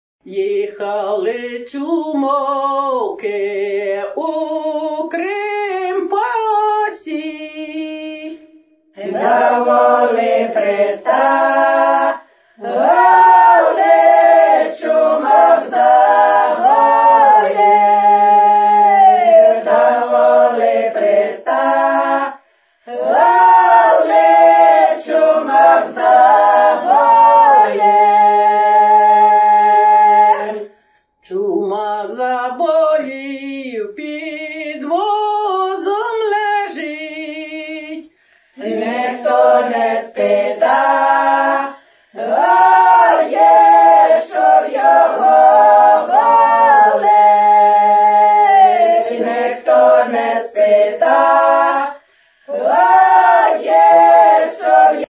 Folk (248)